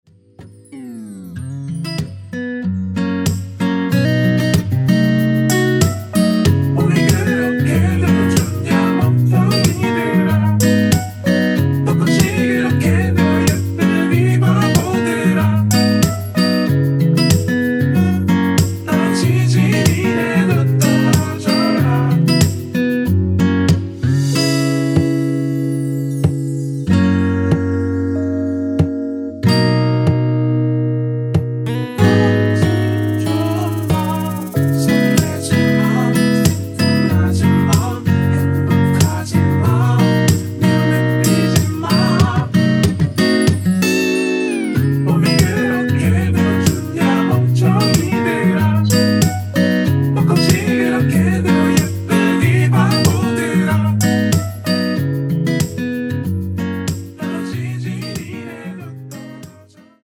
원키에서(-2) 내린 코러스 포함된 MR 입니다.(미리듣기 참조)
앞부분30초, 뒷부분30초씩 편집해서 올려 드리고 있습니다.